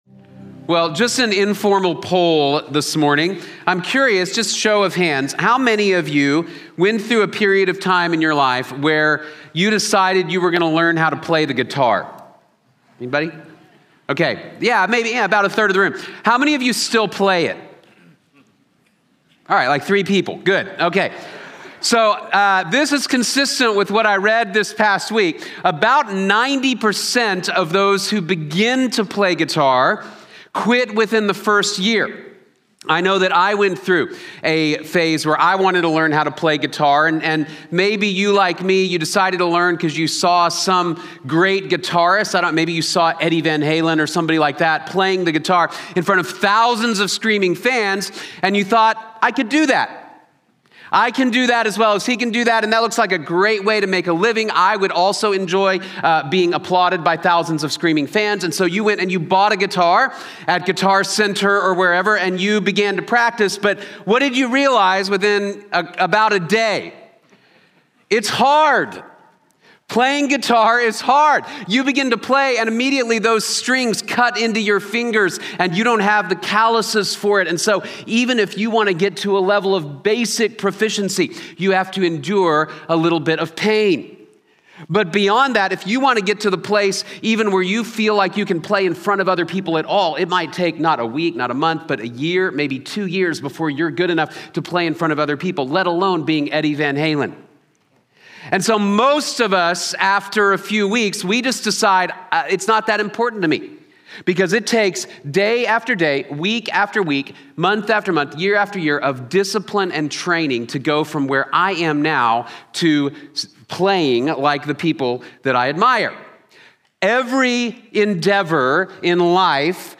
Caminar por el Espíritu | Sermón | Iglesia Bíblica de la Gracia